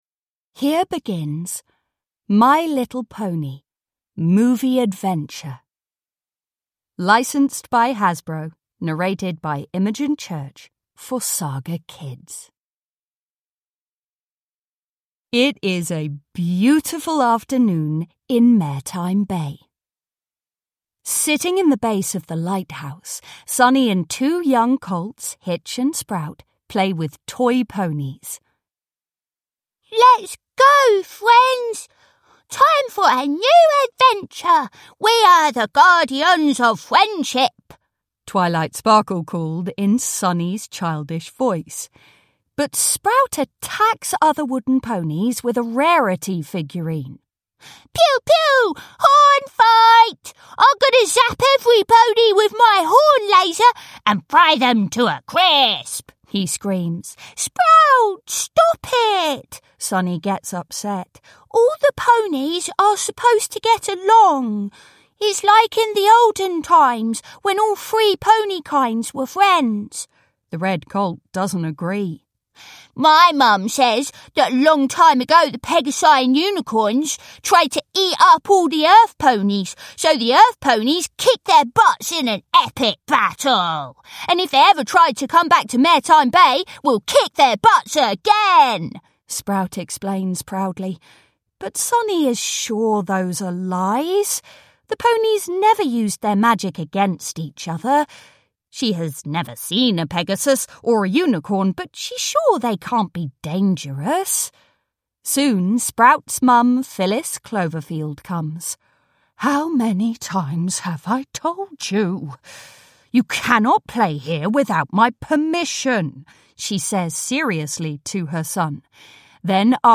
My Little Pony - The New Generation - Movie Adventure (ljudbok) av Hasbro France SAS